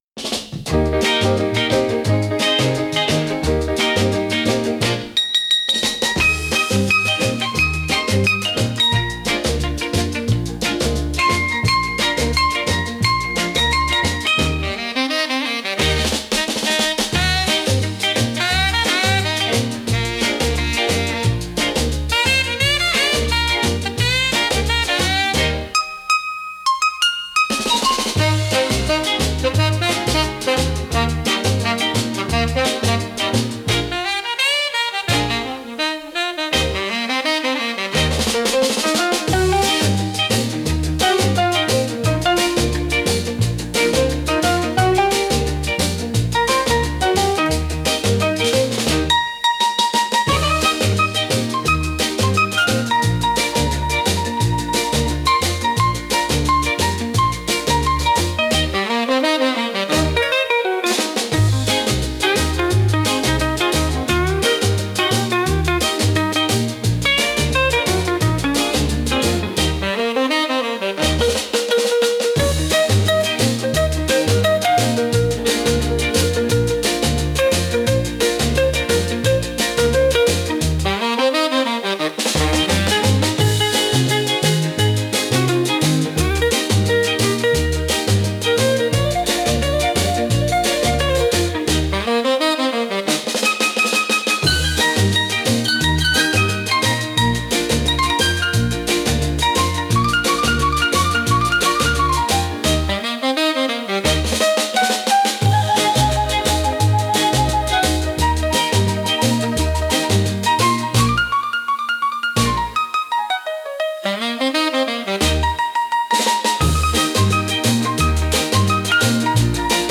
Instrumental / 歌なし
リズムがはっきりしていてノリやすいため、キッズやジュニア選手が、背伸びせず等身大の可愛さで元気に踊るのに最適です。